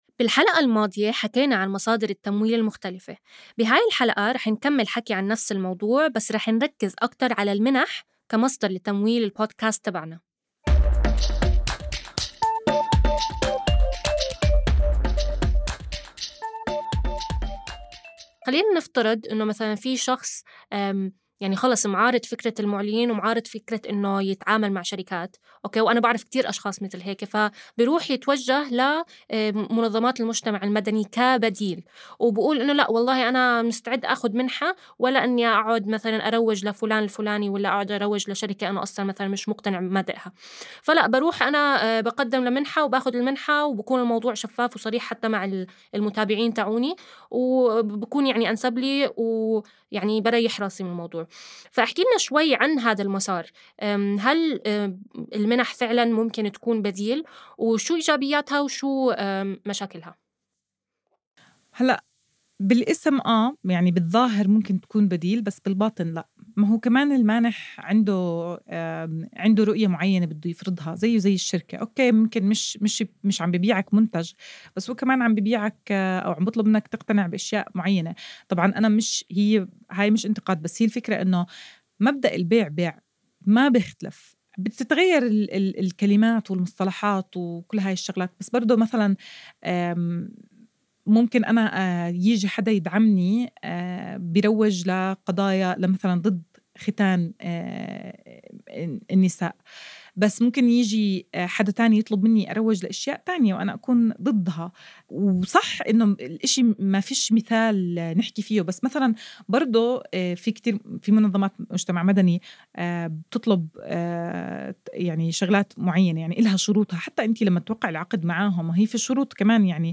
ابتداءً من الحلقة ١٤ وحتى نهاية المساق لن تجدوا تفريغ لهذه الحلقات حيث ستكون الحلقات حوارية وتكمن قيمتها في الاستماع لها.